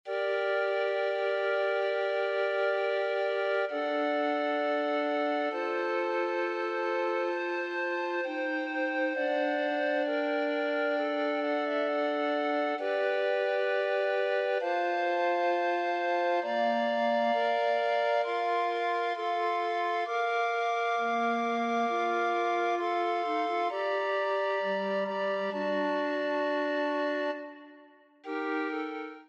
SATB recorders
Ave Verum Corpus , a Latin motet from his Gradualia collection, reflects his Catholic faith and was likely intended for private devotion. Performing barless music aligns with Renaissance rhythmic practices, where notation lacked modern bar lines, allowing natural phrasing shaped by the text. Instead of strict meter, musicians followed the tactus—a steady pulse around 60-80 bpm.